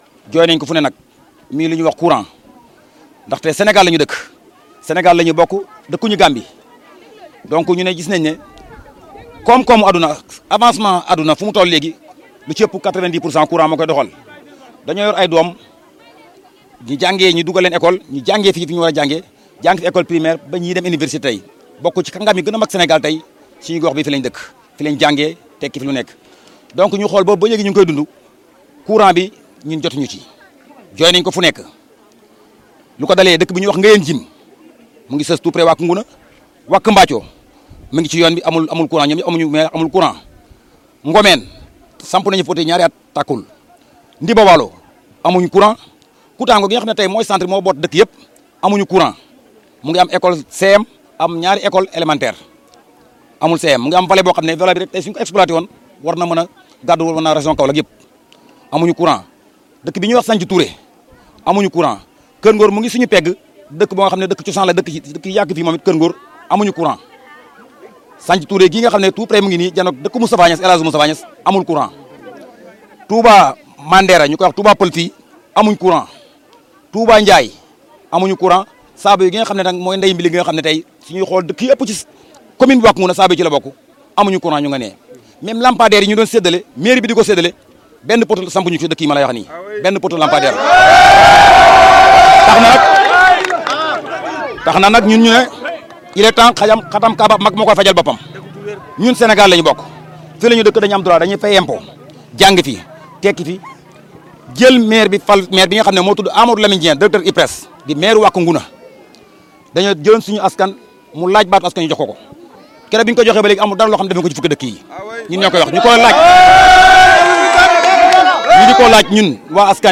Des populations de Koutango, un village de la commune de Wack Ngouna, dans le département de Nioro, ont investi la rue pour réclamer de l’électricité, a constaté Kaolack Infos.